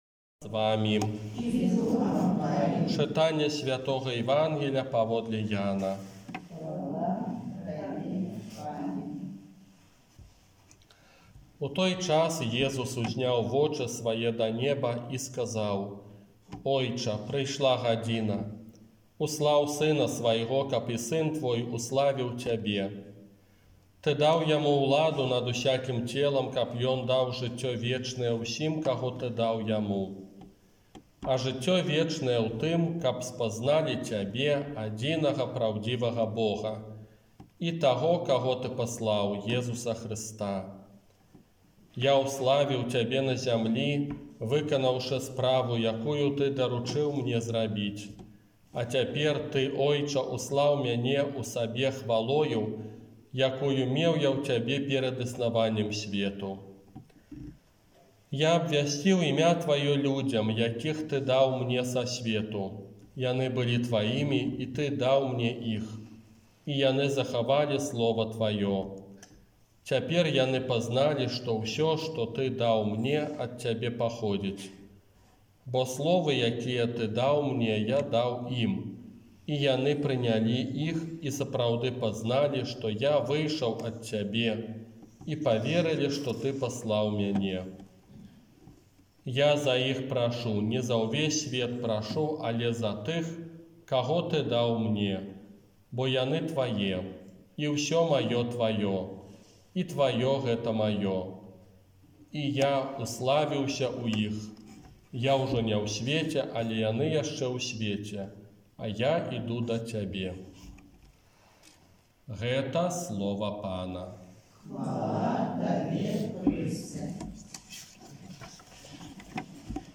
ОРША - ПАРАФІЯ СВЯТОГА ЯЗЭПА
Казанне на сёмую велікодную нядзелю